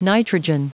Prononciation du mot nitrogen en anglais (fichier audio)
Prononciation du mot : nitrogen